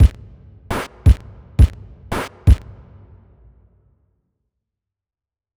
drums2.wav